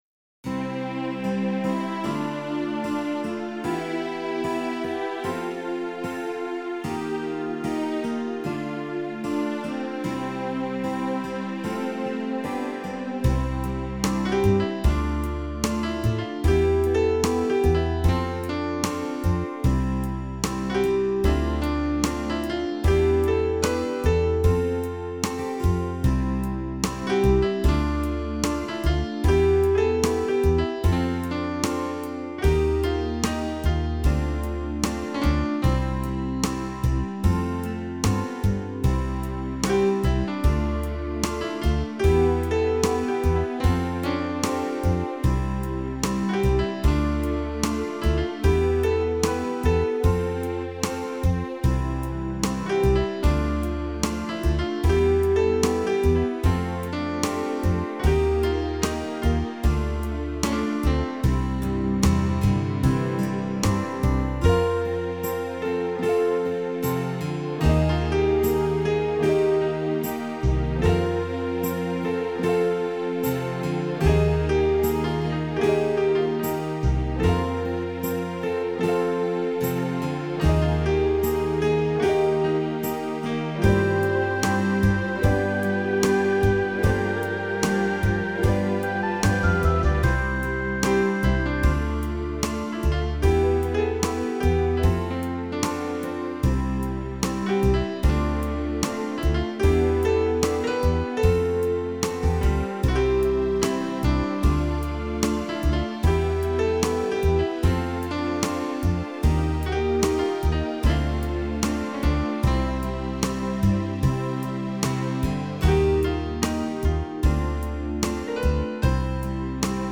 Intensely romantic and sweet, every bride wants this one.